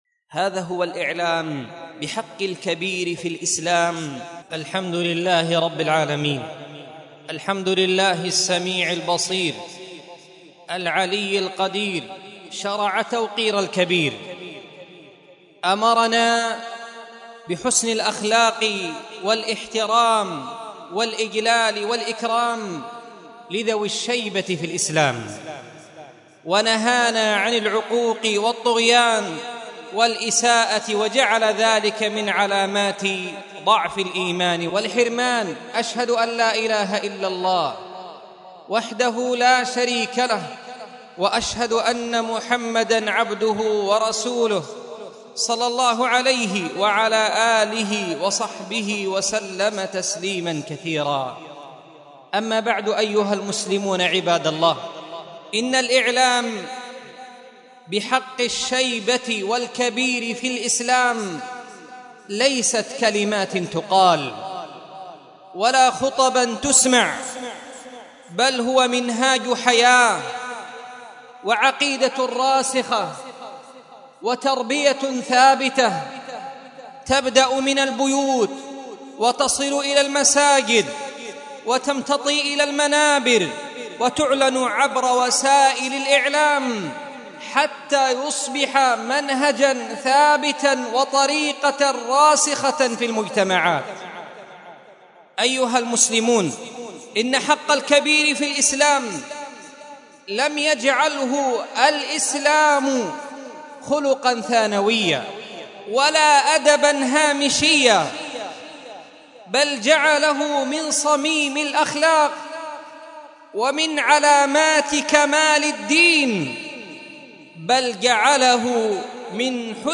مسجد درة عدن محافظة عدن حرسها الله